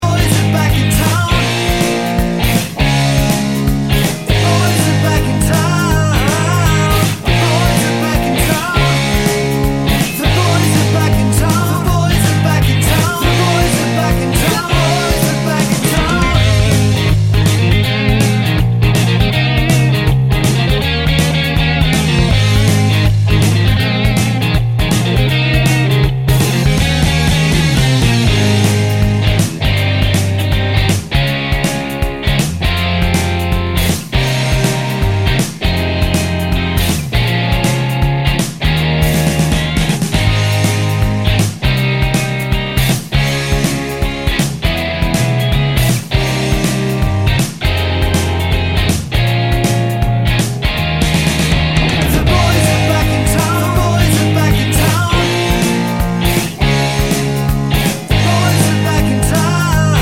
no Backing Vocals Irish 4:28 Buy £1.50